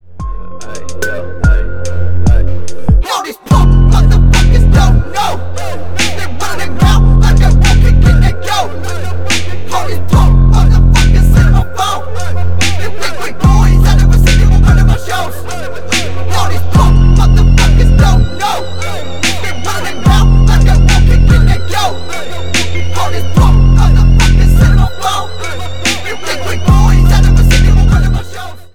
bass boosted
громкие
рэп